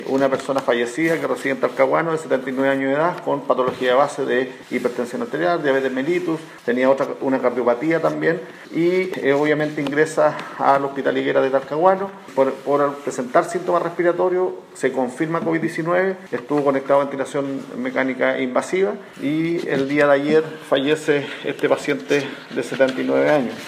Respecto a la persona fallecida, el seremi de Salud, Héctor Muñoz, dijo que se trata de un hombre de 79 años, quien tenía patologías de base y que fue hospitalizado por complicaciones respiratorias en el Hospital Las Higueras en Talcahuano, misma comuna donde residía.
cua-covid-martes-seremi-salud.mp3